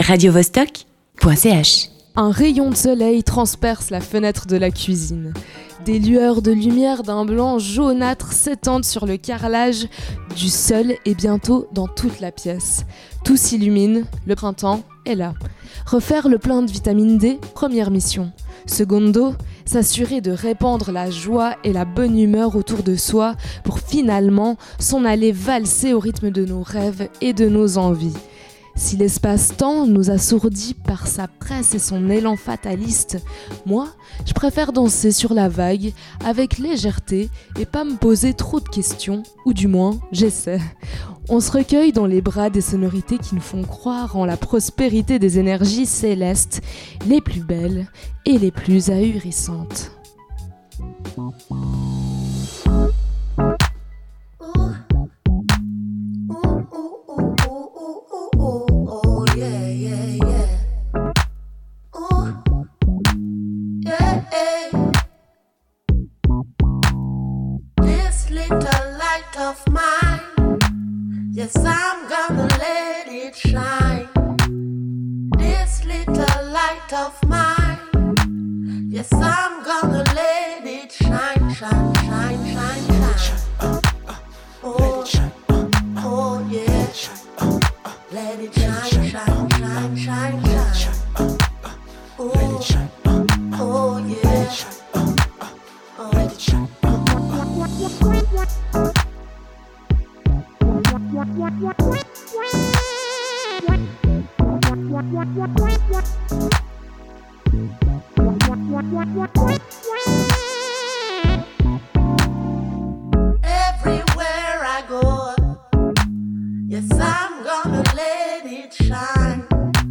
Chronique